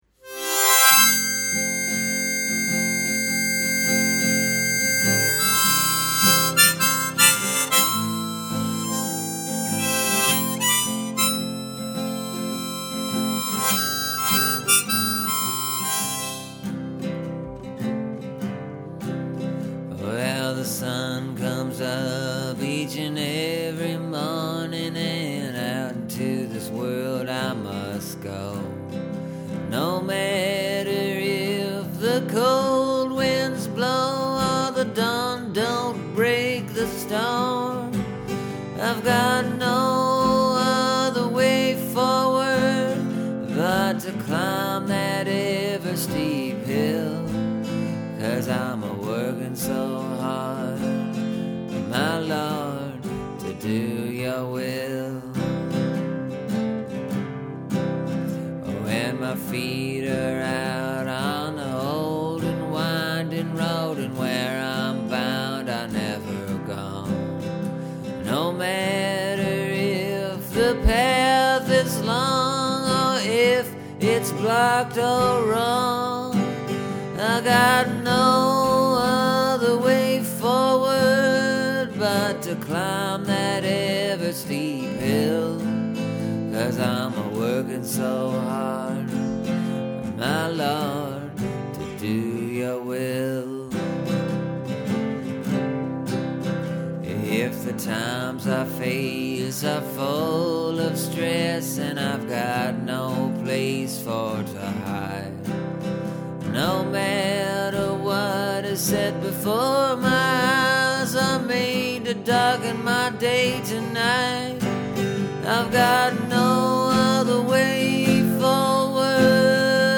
I’ve really been listening to a lot of gospel tunes lately, and while this song ain’t necessarily a straight up Gospel tune it’s definitely headed in that direction.
But it’s also a folk tune, no doubt.